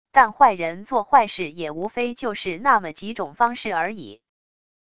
但坏人做坏事也无非就是那么几种方式而已wav音频生成系统WAV Audio Player